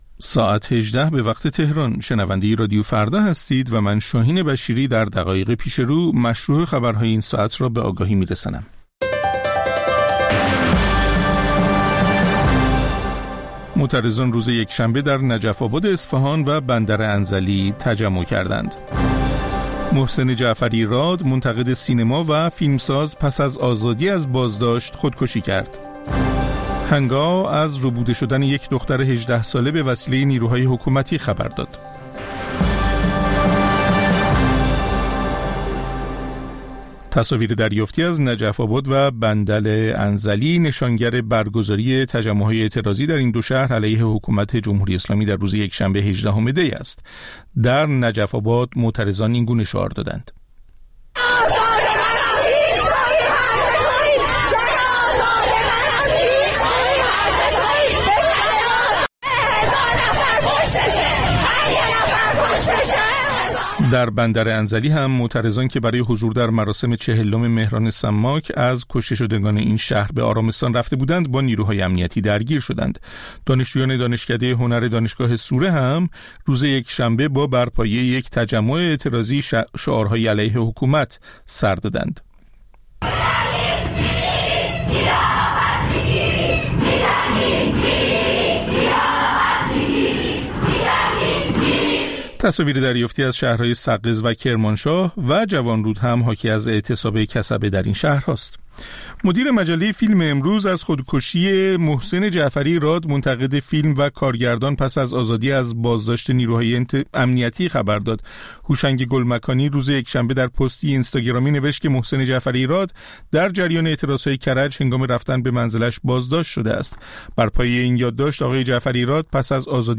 خبرها و گزارش‌ها ۱۸:۰۰